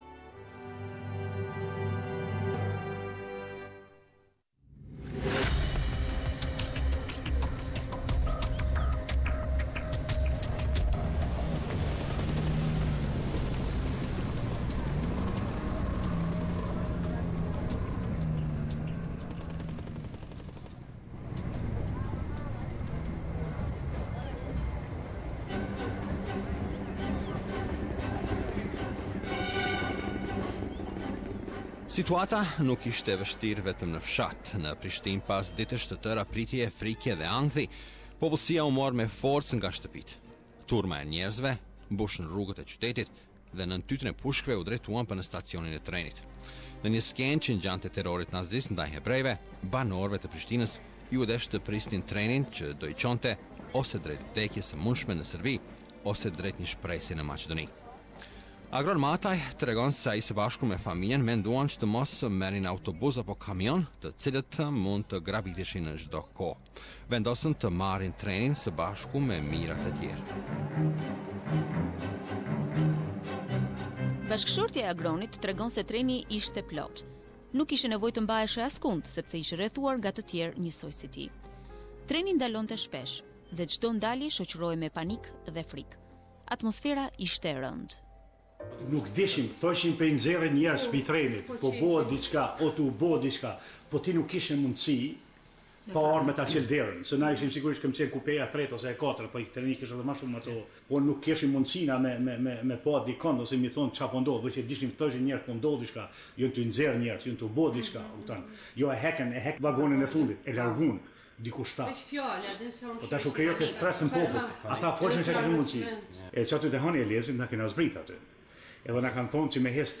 Dreka e Fundit - Documentary - Part 2